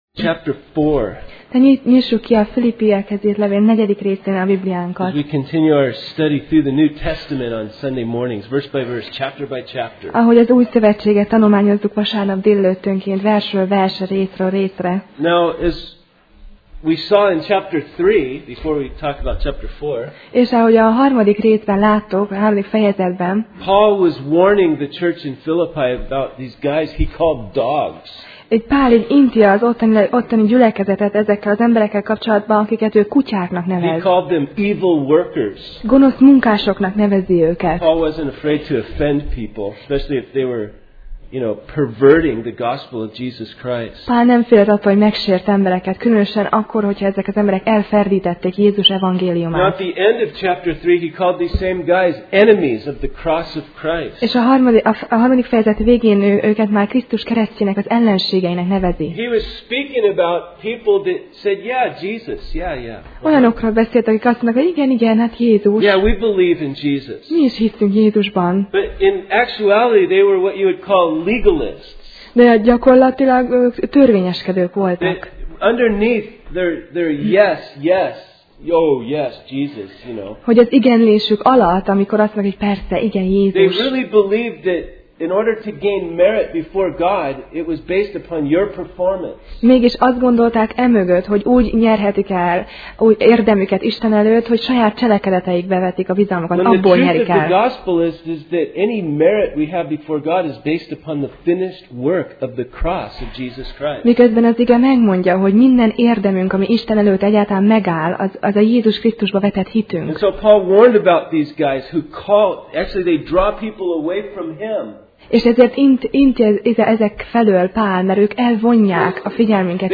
Filippi Passage: Filippi (Philippians) 4:4-8 Alkalom: Vasárnap Reggel